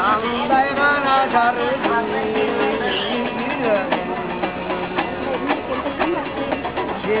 The "kommuz" is the instrument that is the heart and soul of Kyrgyz music. It is a simple 3 stringed instrument made from the wood of the archa tree.
kommuz.wav